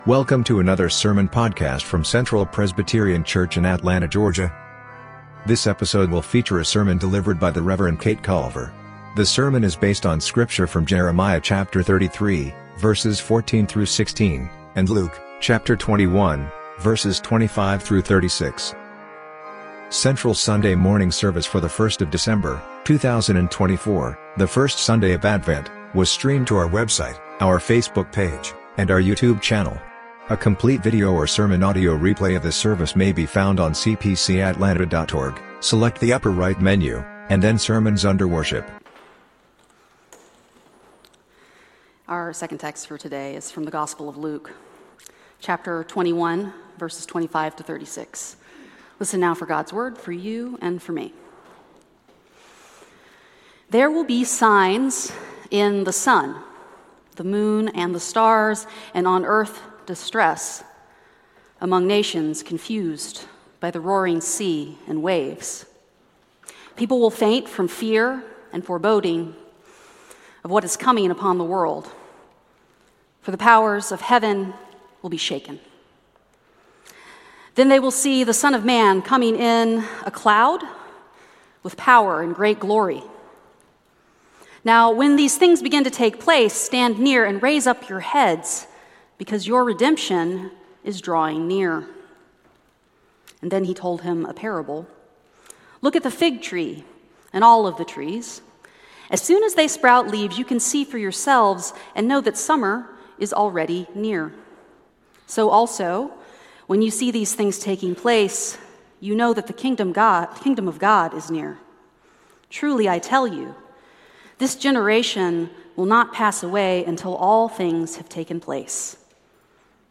Sermon Audio:
Passage: Jeremiah 33:14-16, Luke 21:25-36 Service Type: Sunday Sermon